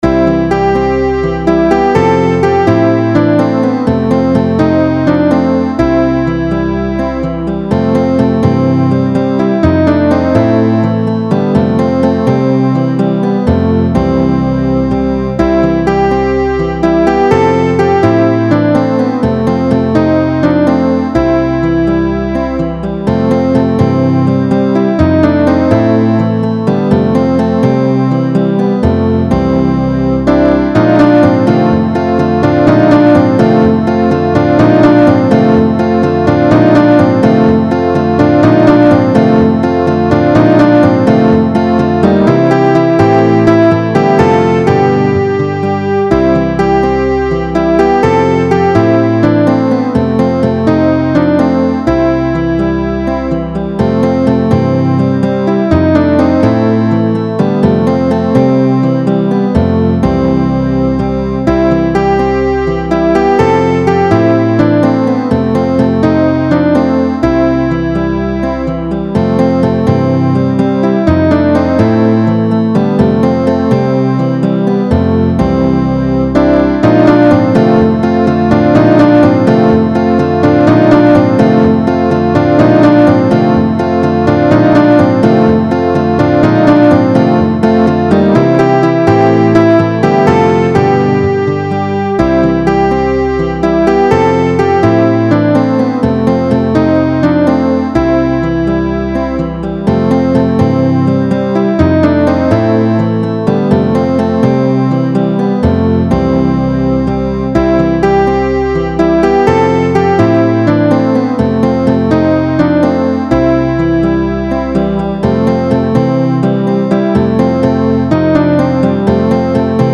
An upbeat spiritual.